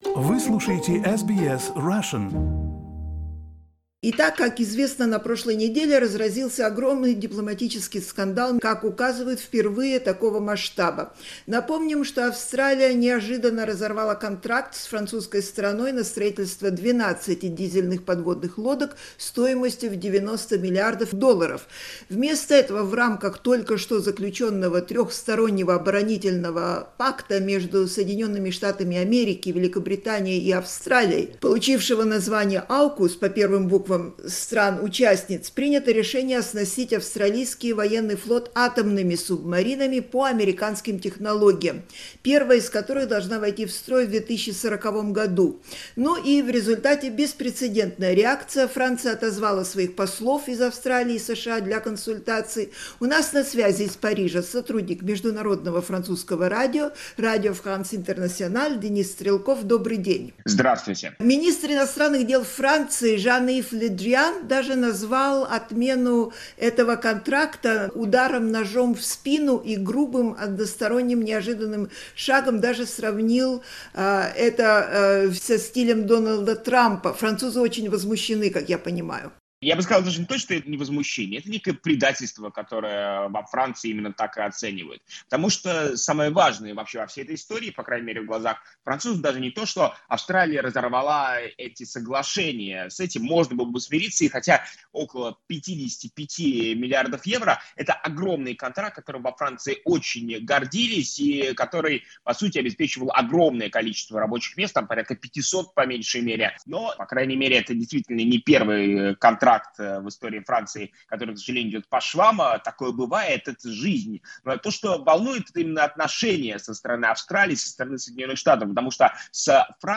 О реакции на разрыв контракта французской стороны слушайте в нашем интервью.